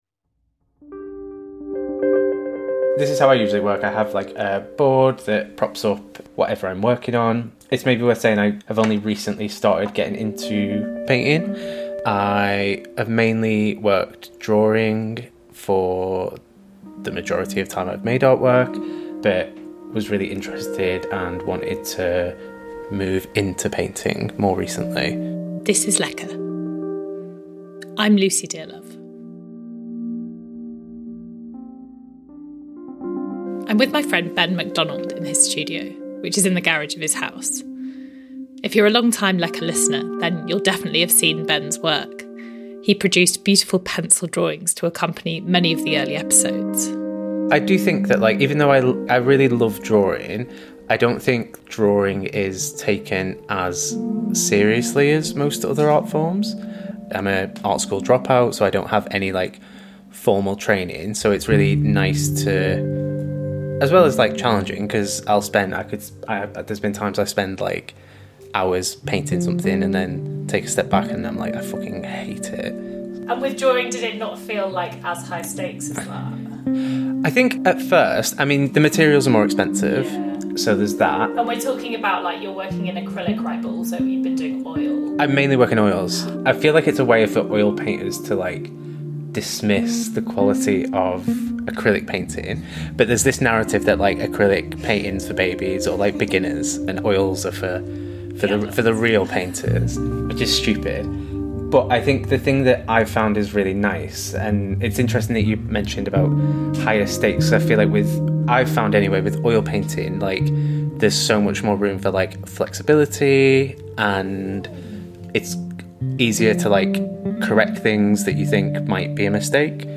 Lecker is a podcast about how food shapes our lives. Recorded mostly in kitchens, each episode explores personal stories to examine our relationships with food – and each other.